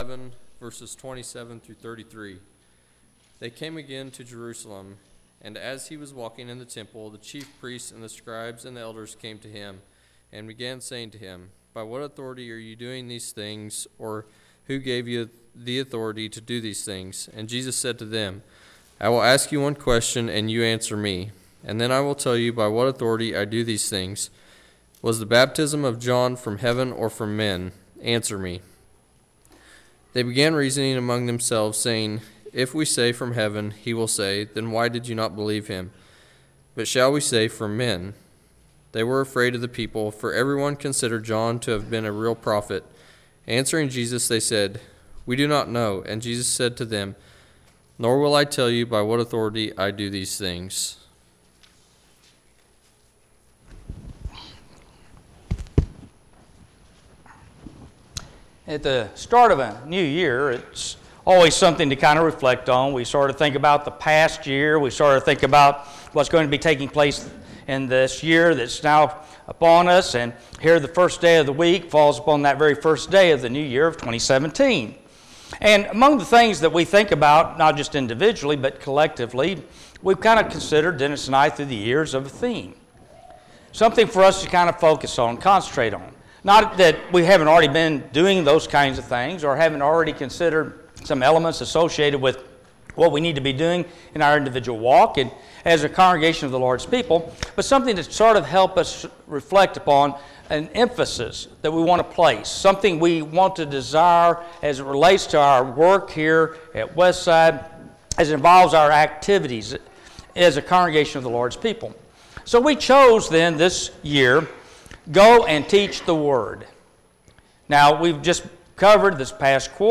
Teachable Moments – Westside church of Christ